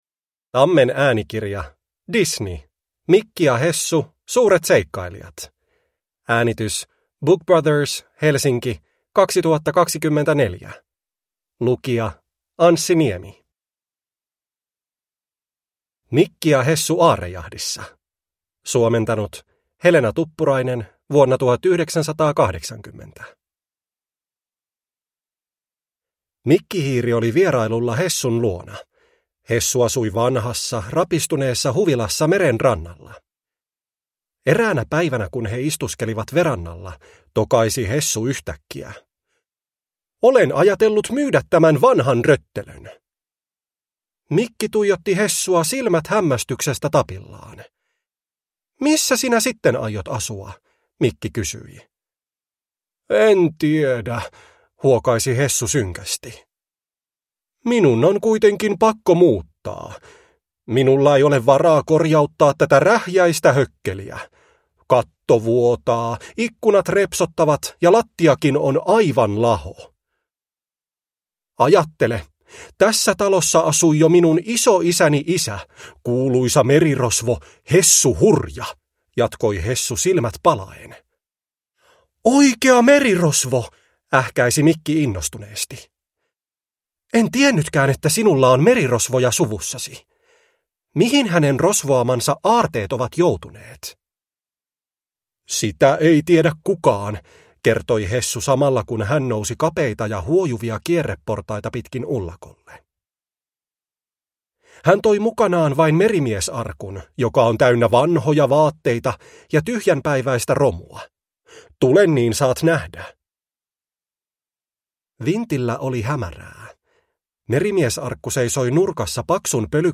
Mikki ja Hessu, suuret seikkailijat – Ljudbok